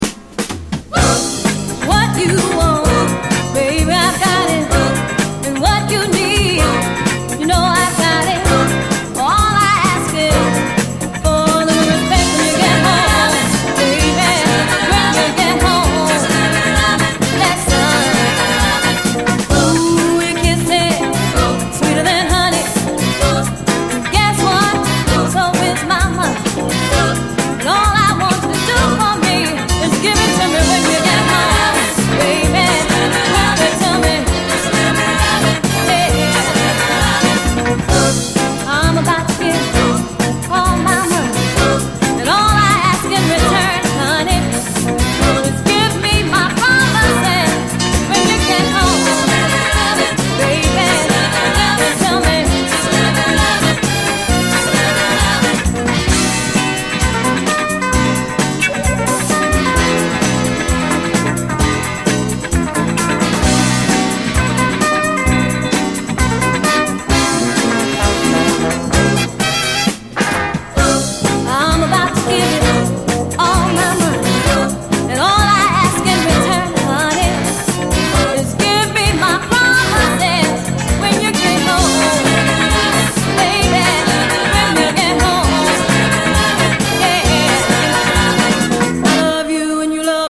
】1977年リリースのオリジナルは4,5万は下らない激レア・ファンキー・ソウル〜ディスコ・アルバムが正規復刻！